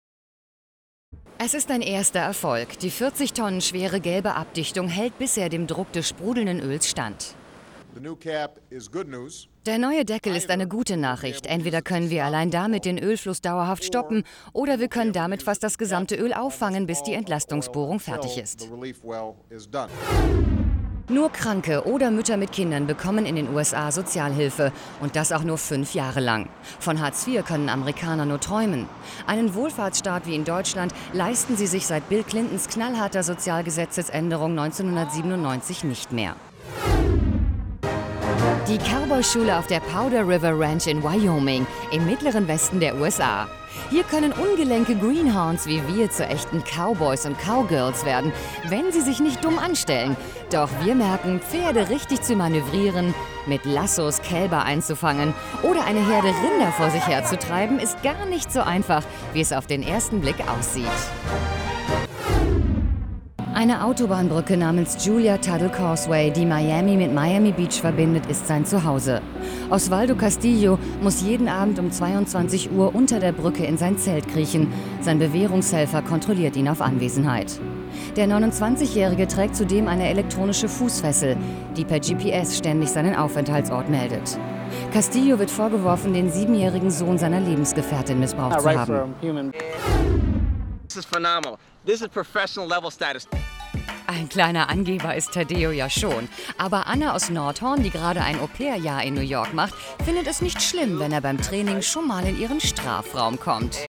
seit über 20 Jahren Profi-Sprecherin, bekannte Stimme aus Funk und TV, Werbung und Service-Telefonie, volle, warme, weibliche Stimme, sehr wandelbar von werblich über seriös/ernst zu informativ und freundlich
Kein Dialekt
Sprechprobe: Sonstiges (Muttersprache):